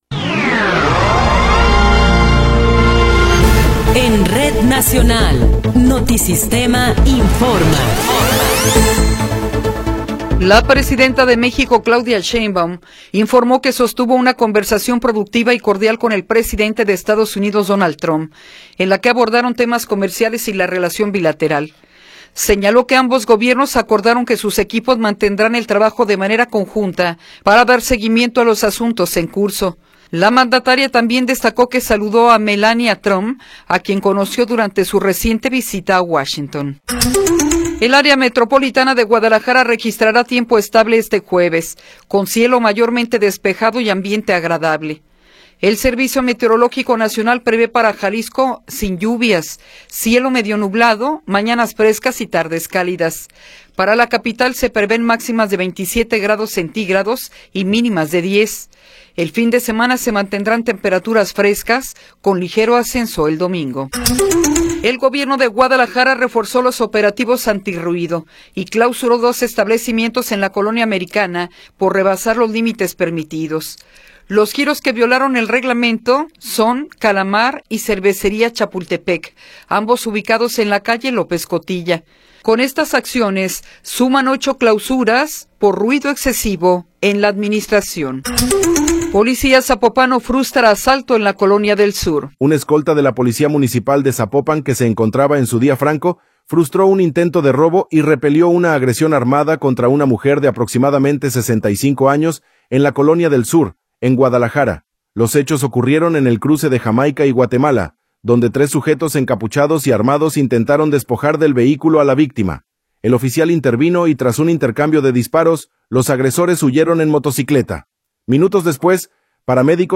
Noticiero 10 hrs. – 29 de Enero de 2026
Resumen informativo Notisistema, la mejor y más completa información cada hora en la hora.